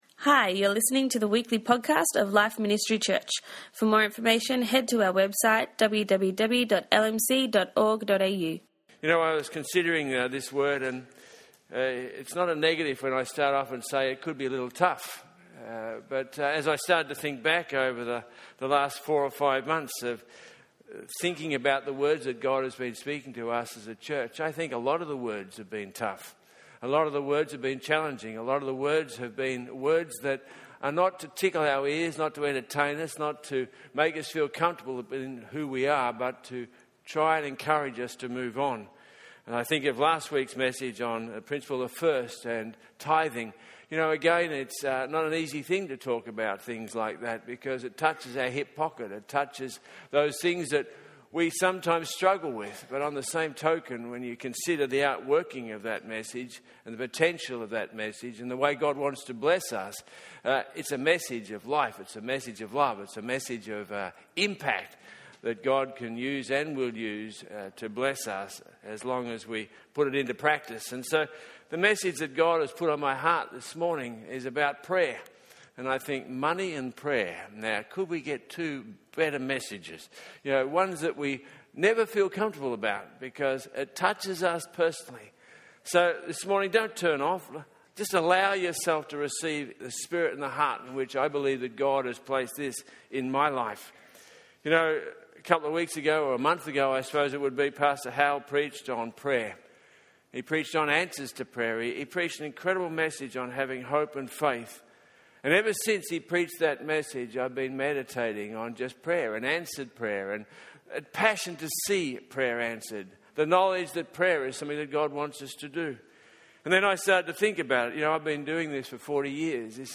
This message on Prayer is both an encouragement and a challenge, as we as a church seek what God is asking for us in the lead up to next year.